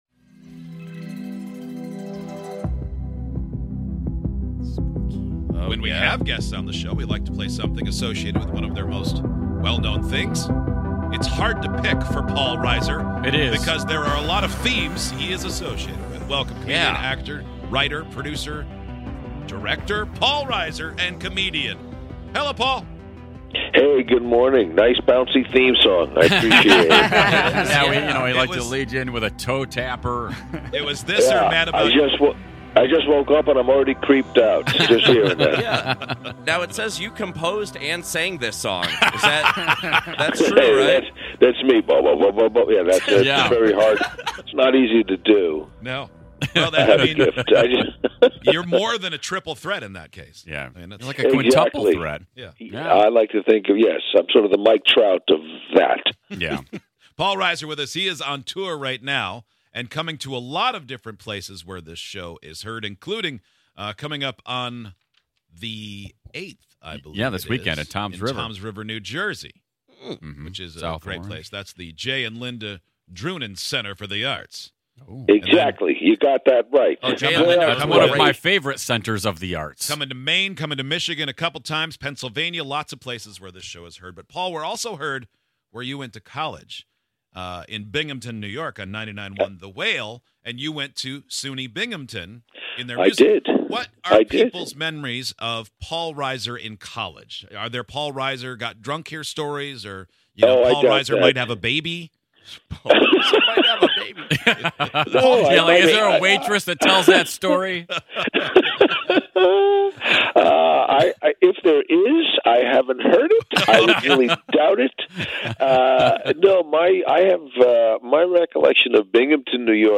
On today's show, we interviewed the actor, comedian, and writer Paul Reiser.